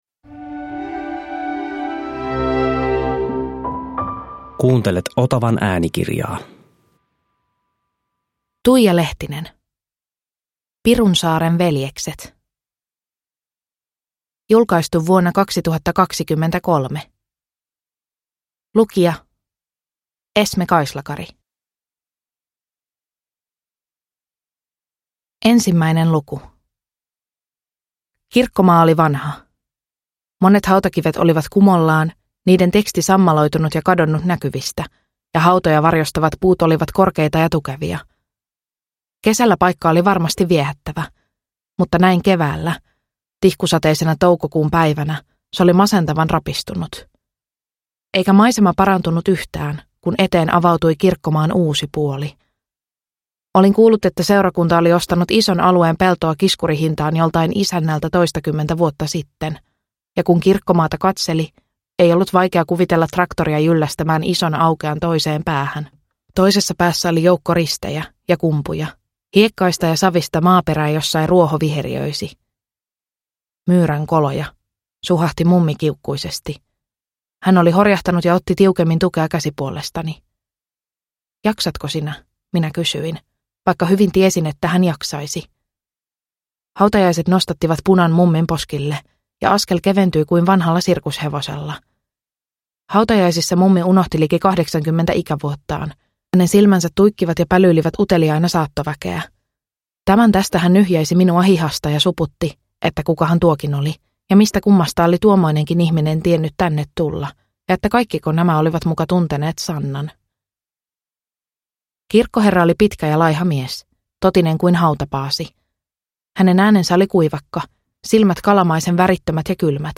Pirunsaaren veljekset – Ljudbok – Laddas ner